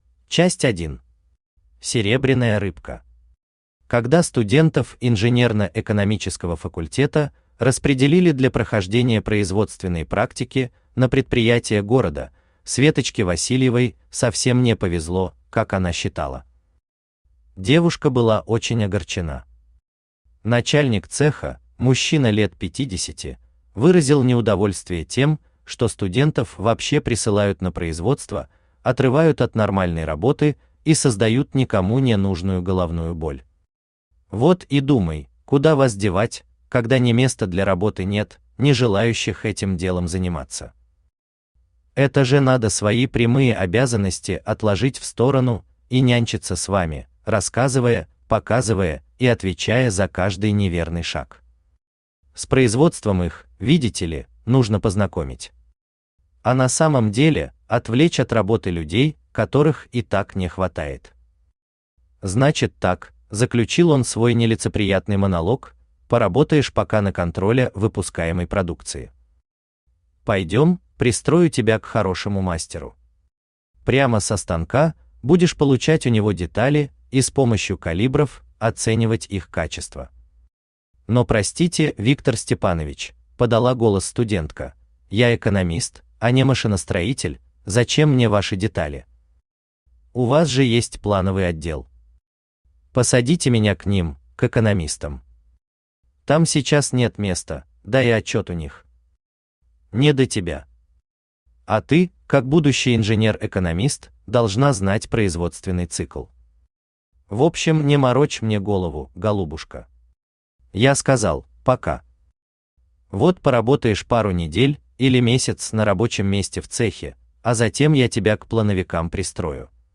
Аудиокнига Калейдоскоп времени | Библиотека аудиокниг
Aудиокнига Калейдоскоп времени Автор Жанна Светлова Читает аудиокнигу Авточтец ЛитРес.